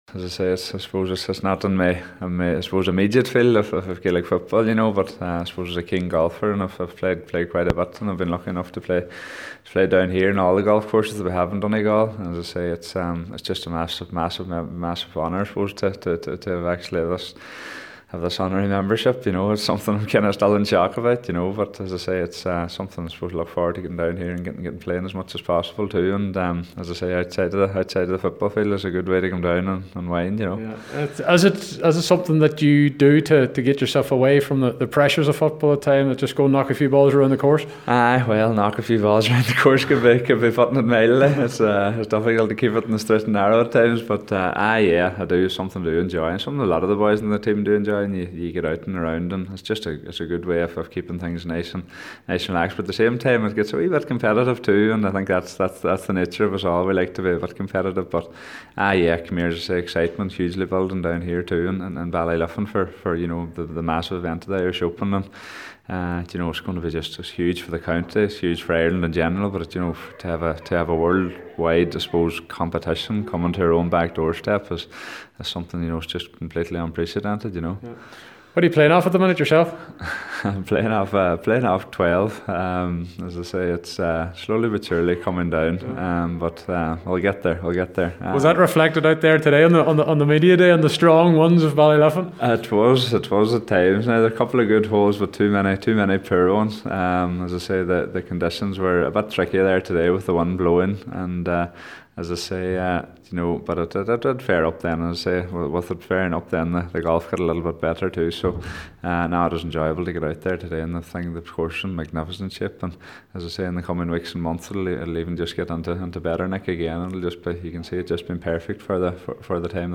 Michael Murphy, Captain of the Donegal Senior GAA team was announced as an honorary member of Ballyliffin Golf Club during the media day.